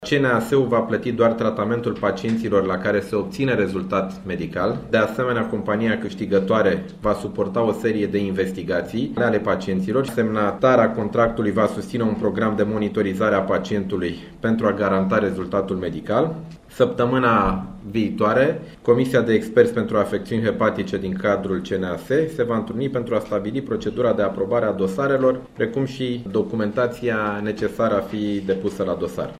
Potrivit înţelegerii, bolnavii vor fi mai întâi investigaţi pe cheltuiala firmei, care se obligă de asemenea să-i monitorizeze pe toată durata tratamentului. Ministrul Sănătăţii, Nicolae Bănicioiu: